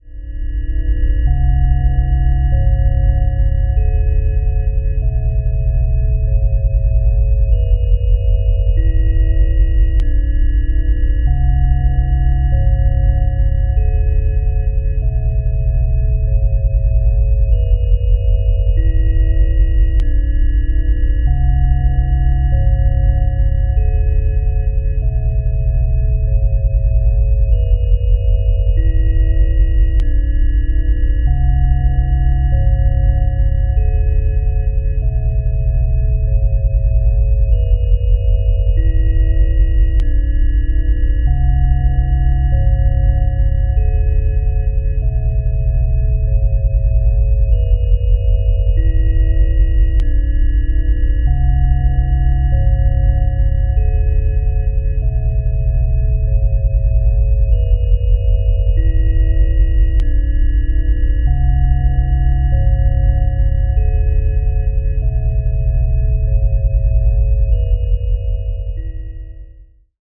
BGM
Speed 20%